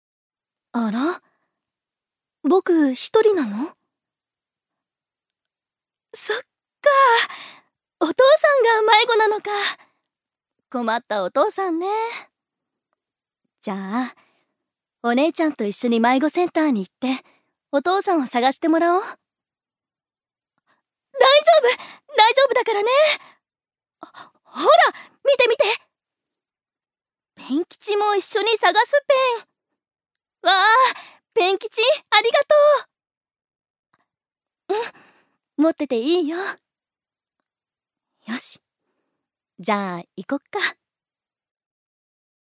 預かり：女性
セリフ４